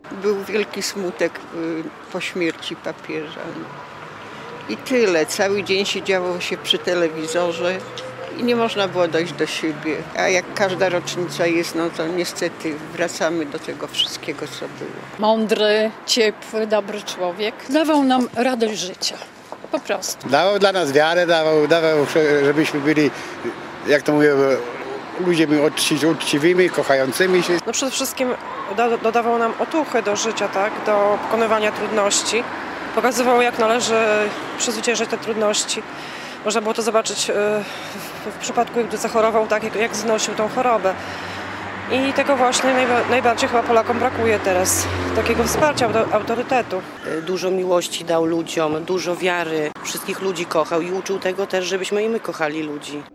– Jego odejście było wielką stratą – mówią mieszkańcy regionu.